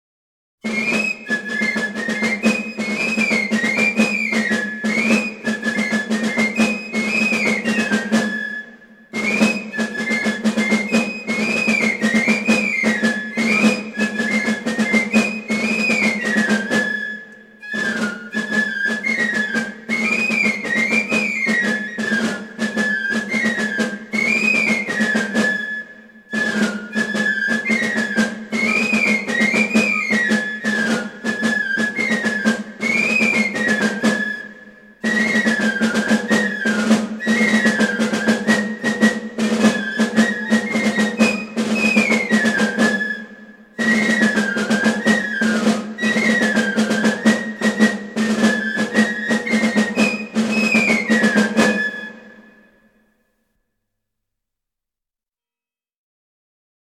Folk Music from French-Speaking Switzerland
Les fifres et tambours de St. Luc